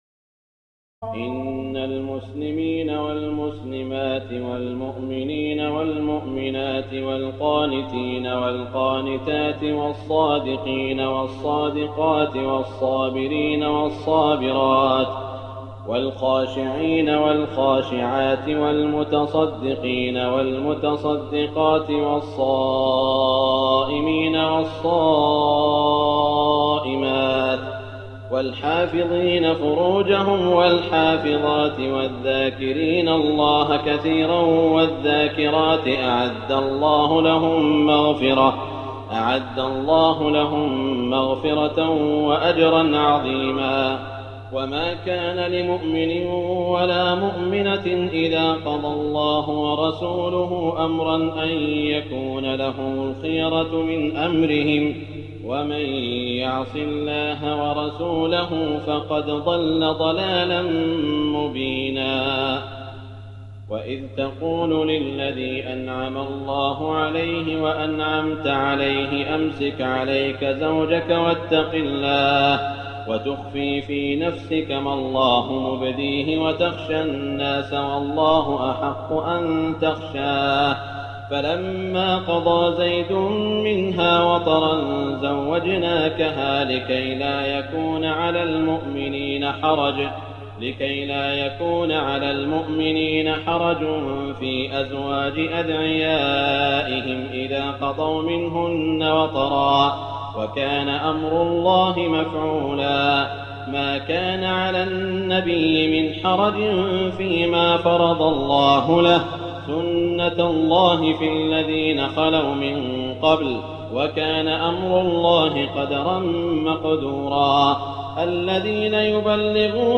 تراويح ليلة 21 رمضان 1419هـ من سور الأحزاب (35-73) وسبأ (1-23) Taraweeh 21 st night Ramadan 1419H from Surah Al-Ahzaab and Saba > تراويح الحرم المكي عام 1419 🕋 > التراويح - تلاوات الحرمين